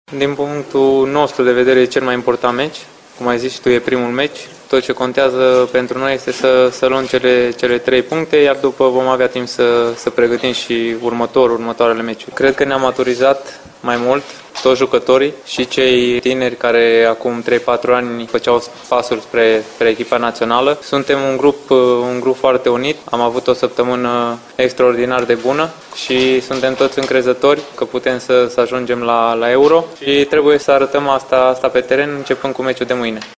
Despre meciul de astăzi a vorbit și Nicolae Stanciu, precizând că în șapte ani de când vine la națională nu a mai văzut așa un grup unit: